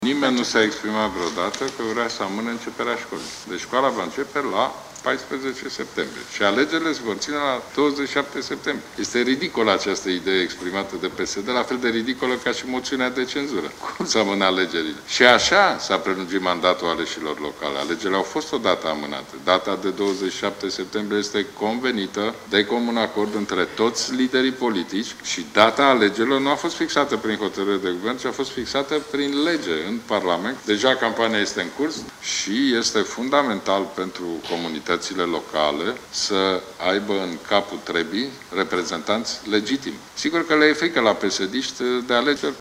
O idee ridicolă – a comentat premierul Ludovic Orban declarația liderului social democrat. Premierul a reamintit că mandatele aleșilor locali au fost deja prelungite și nu există motive pentru încă o amânare: